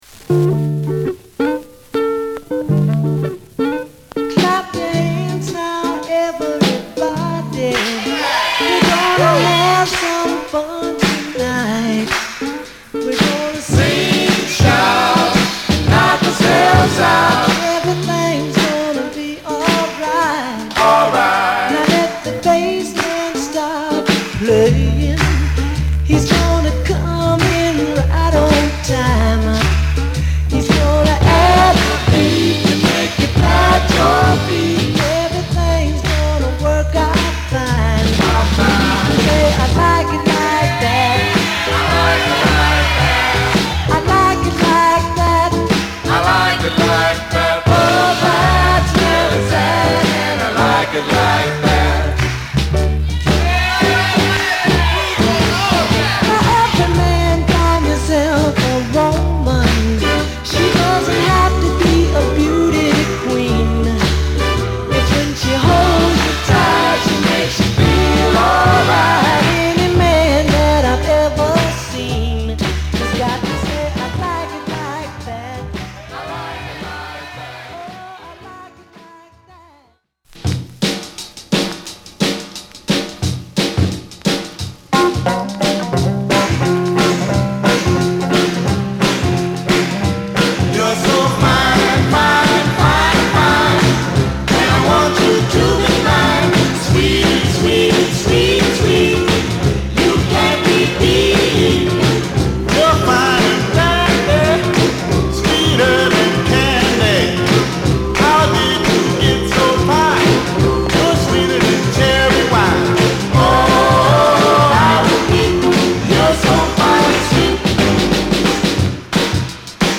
ほっこり緩やかなギターフレーズとハンドクラップに誘われ、ジワジワと高揚してゆくスマイリーなミッドテンポ・ノーザン！